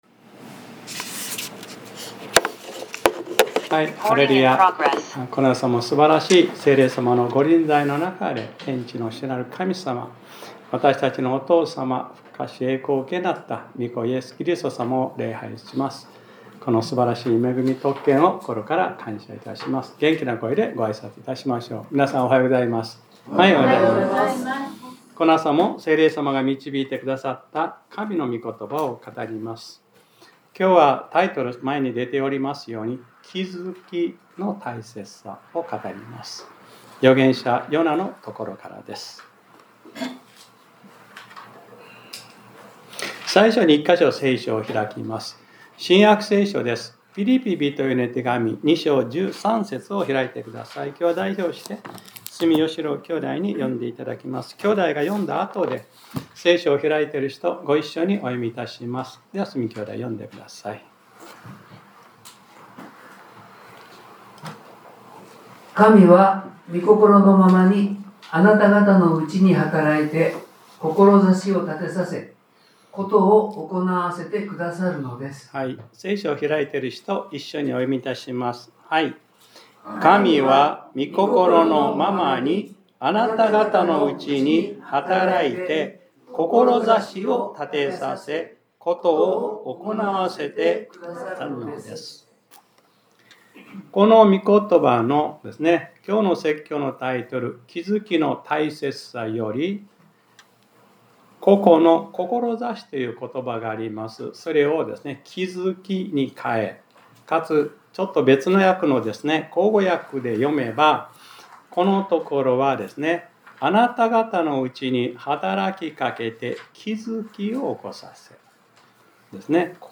2026年02月08日（日）礼拝説教『 気づきの大切さ 』